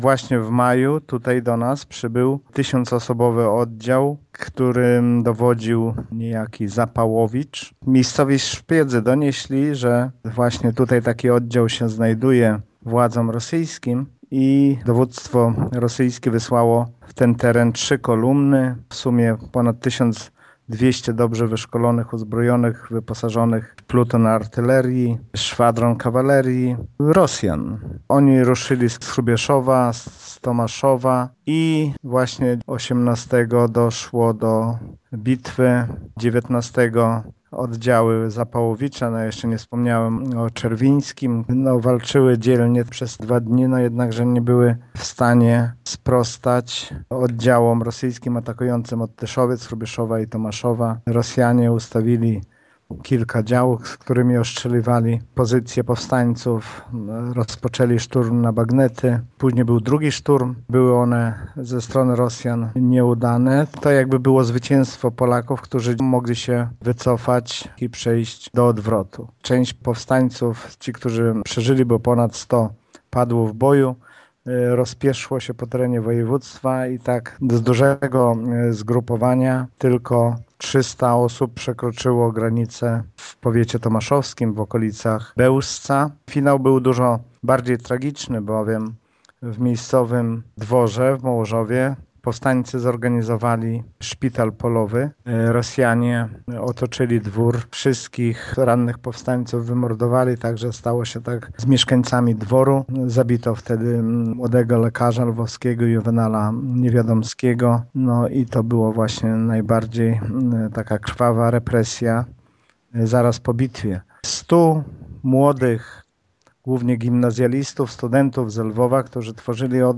18 i 19 maja 1863 roku oddziały powstańcze starły się z Moskalami nie tylko pod Mołożowem, ale także Miętkiem, Starą Wsią i Tuczapami - przypomina wójt Gminy Mircze i historyk Lech Szopiński: